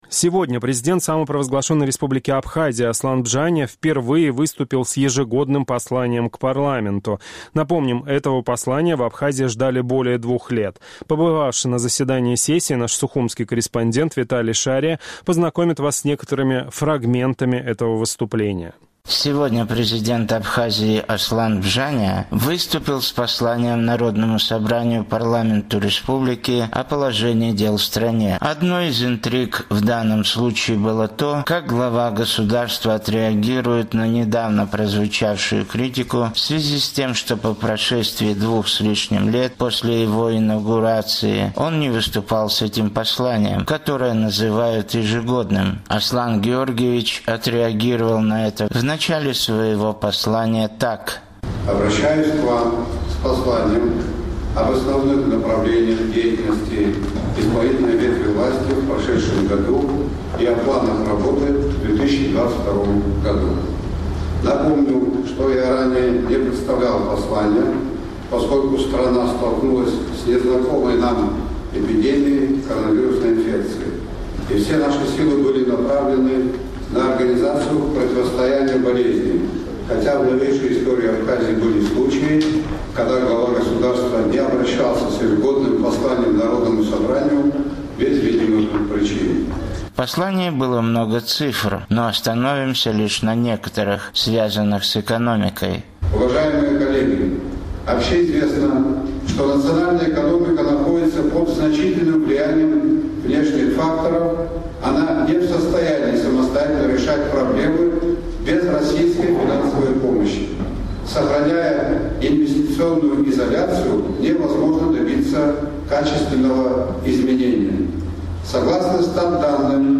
Сегодня президент Абхазии Аслан Бжания выступил с посланием Народному Собранию – Парламенту республики о положении в стране, основных направлениях внутренней и внешней политики государства. Оглашение послания проходило там же, где в апреле первое заседание сессии седьмого созыва парламента.